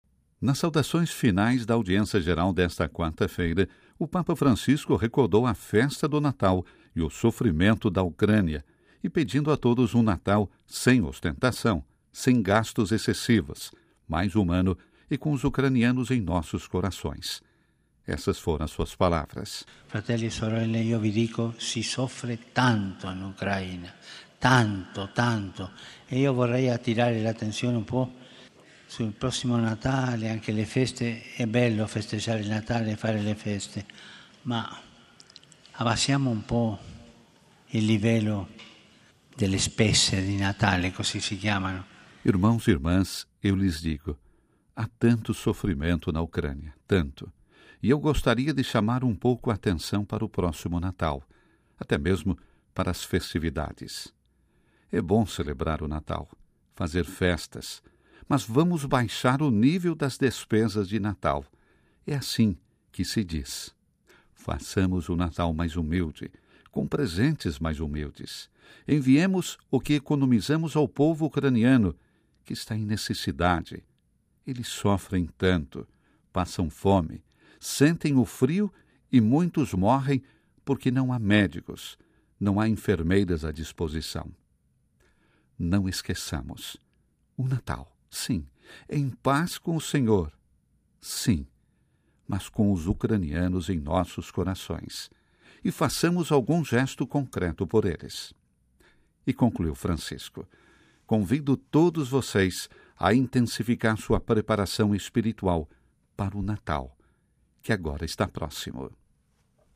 Na Audiência Geral desta quarta-feira (14/12) o Papa Francisco fez um apelo depois da catequese para que sejamos mais humildes também neste Natal.